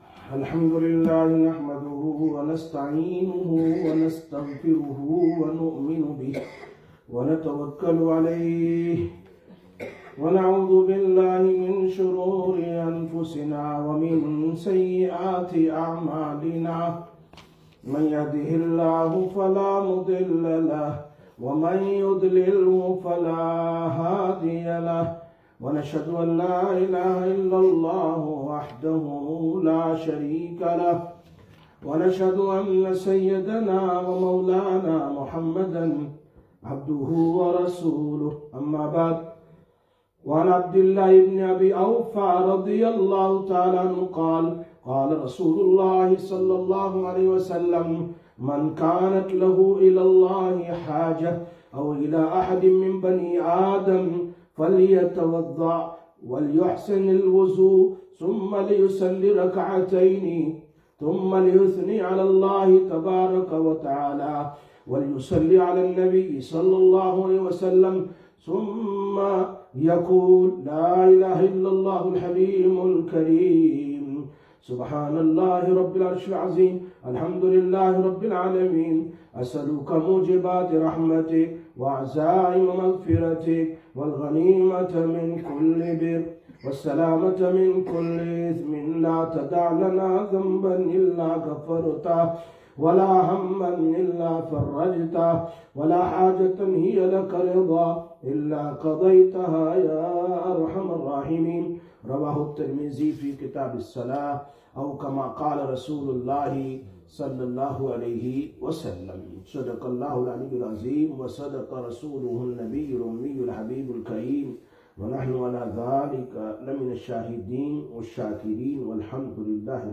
04/02/2026 Sisters Bayan, Masjid Quba